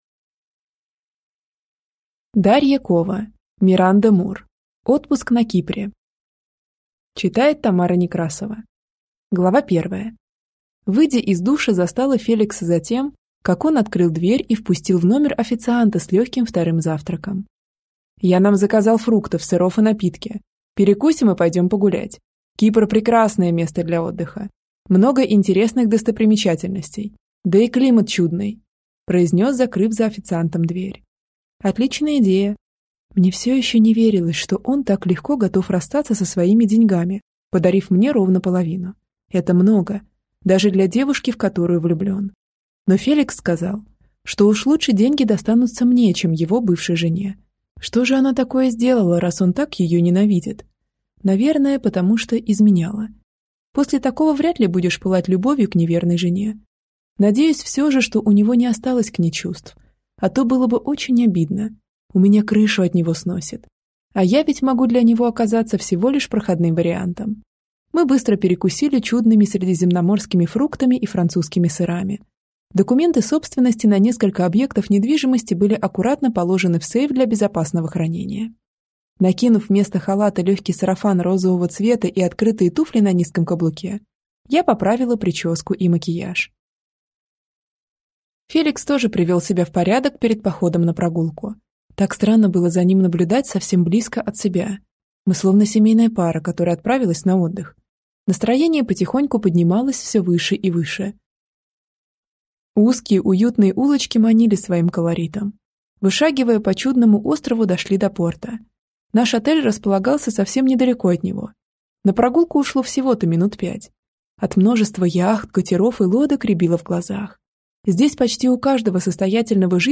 Аудиокнига Отпуск на Кипре | Библиотека аудиокниг